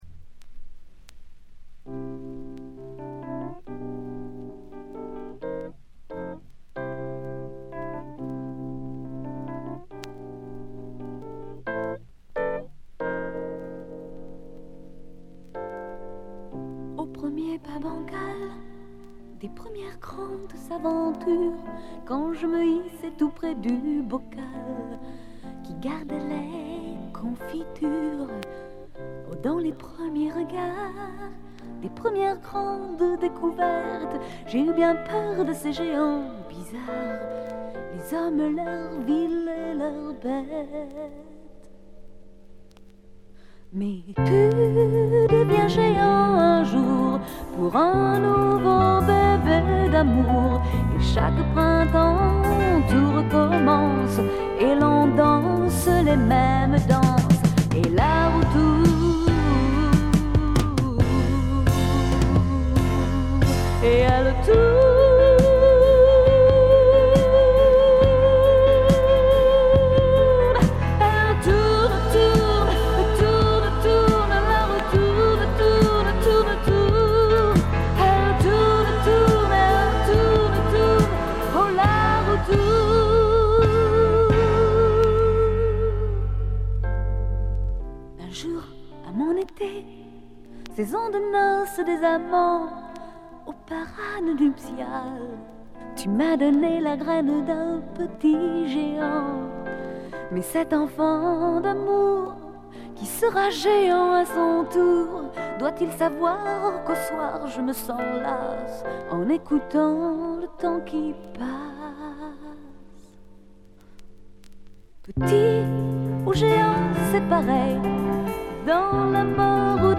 部分試聴ですが静音部でのバックグラウンドノイズ、チリプチ。
70年代から80年代にかけて数枚のアルバムを発表しているフランスの女性シンガー・ソングライター。
フレンチポップ好盤。
試聴曲は現品からの取り込み音源です。